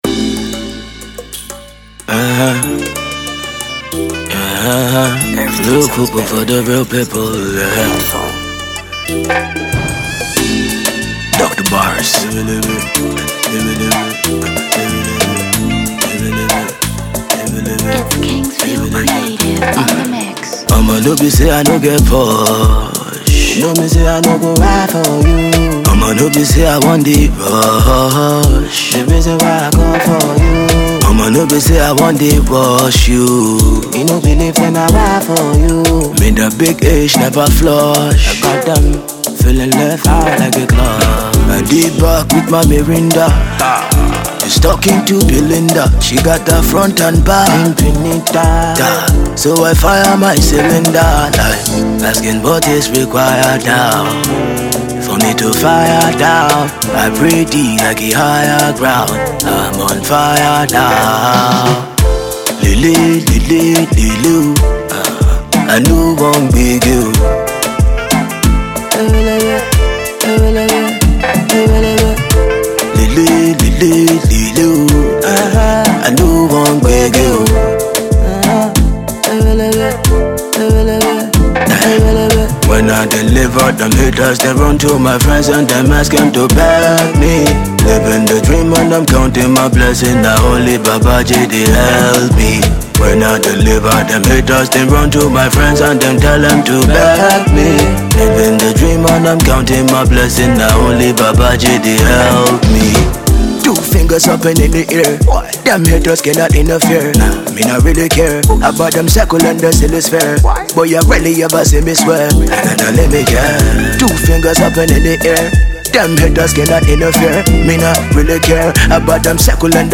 banging Afrobeat song
Saxophonist